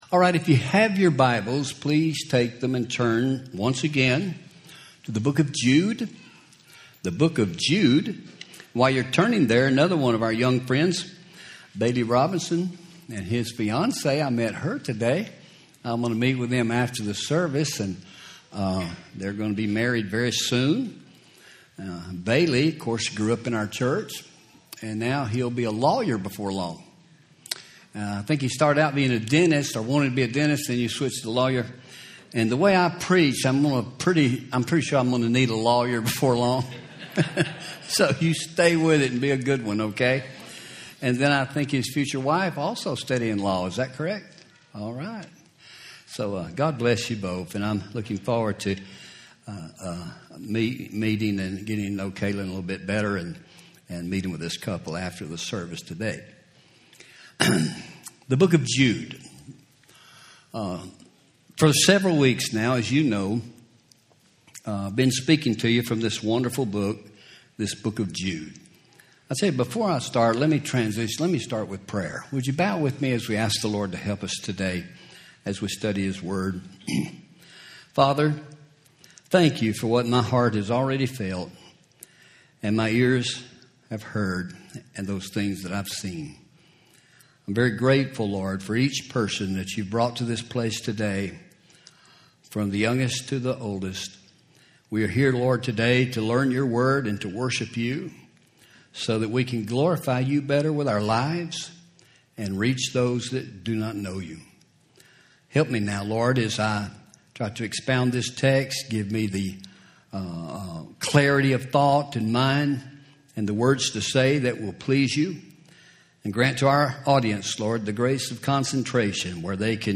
Home › Sermons › Avoiding Apostasy